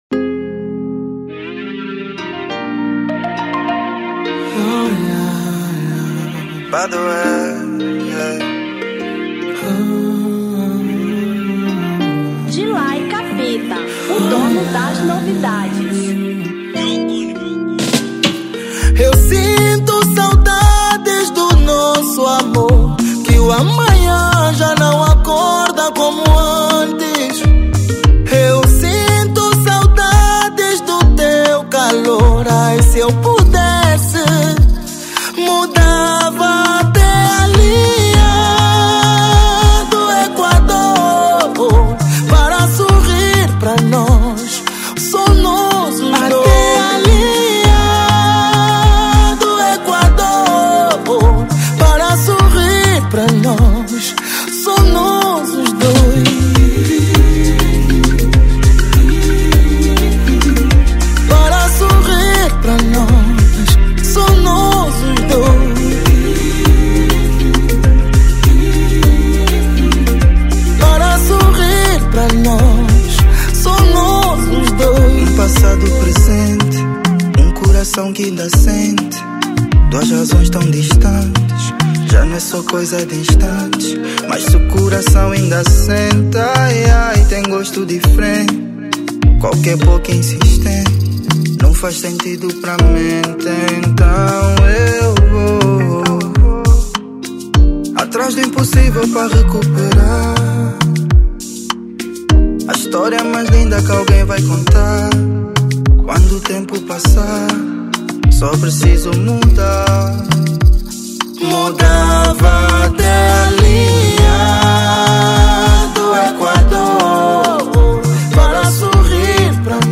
Kizomba 2025